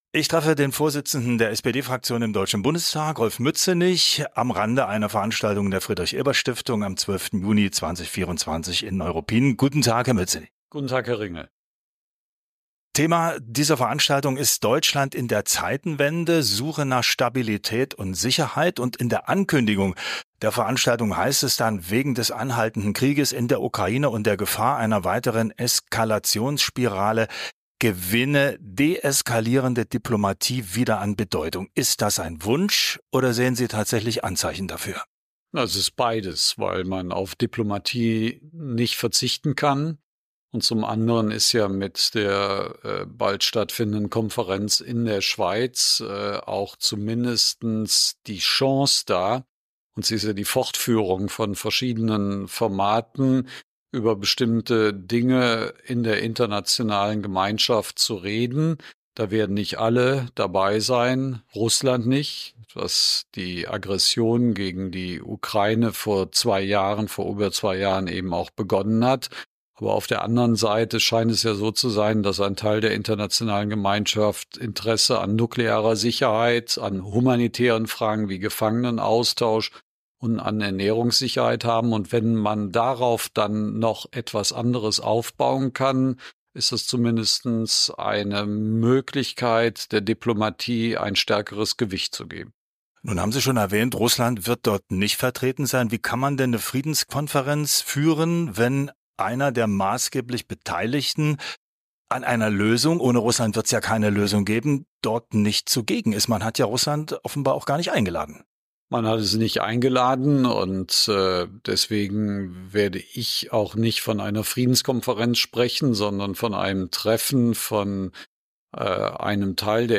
Und über Meinungsfreiheit im Krieg. Ein Telepolis-Interview.